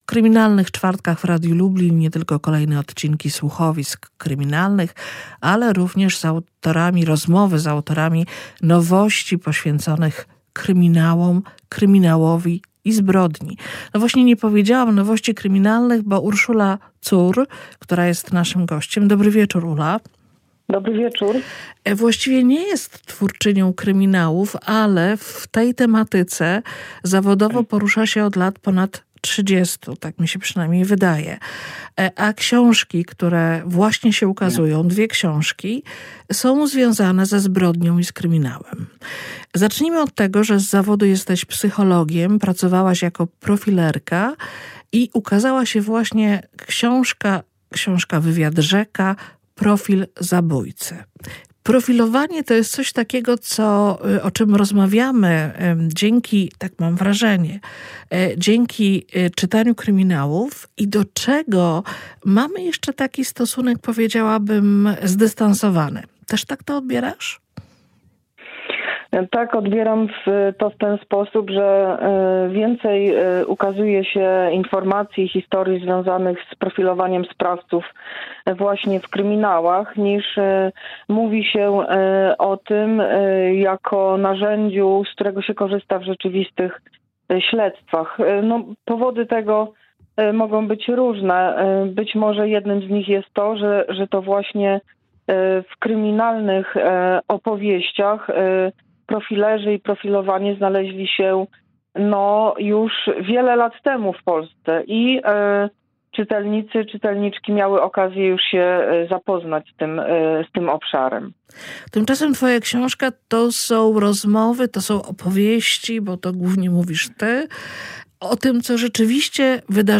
Zapraszam do słuchania trzeciej części słuchowiska „Inspektor Morski”.